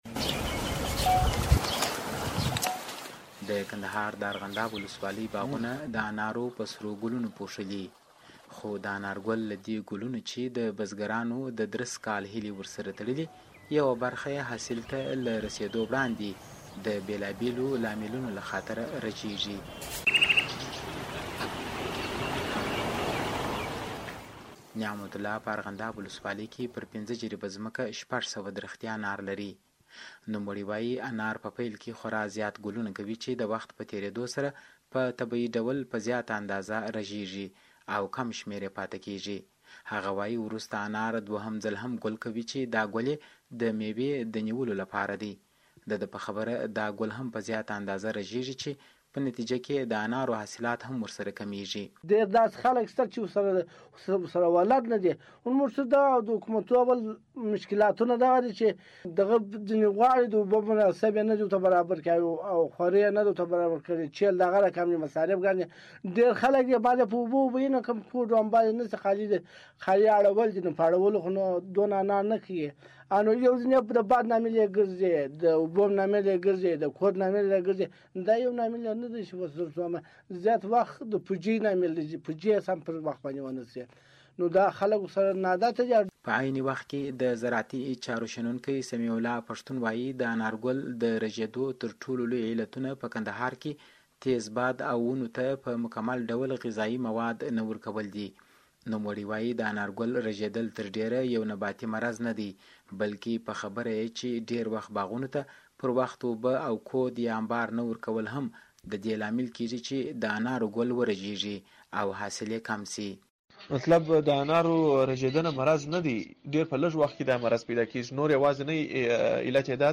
انارګل په اړه راپور